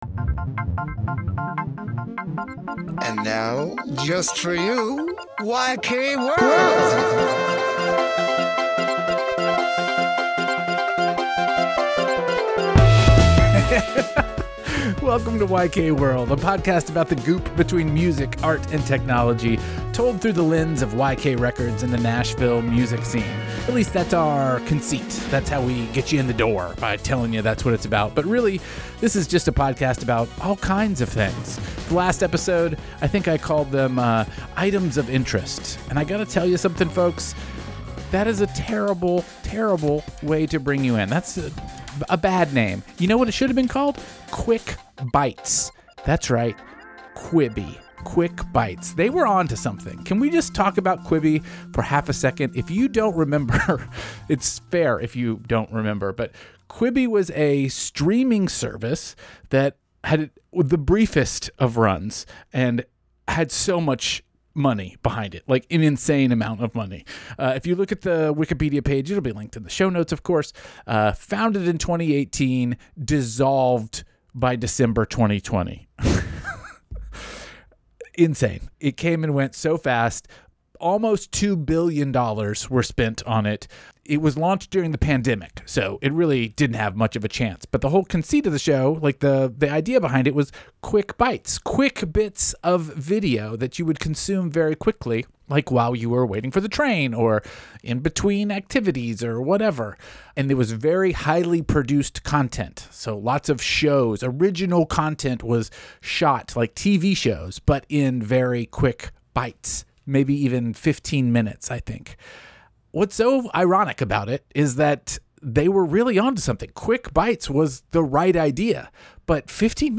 Theme song and episode music